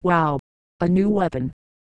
Worms speechbanks
drop.wav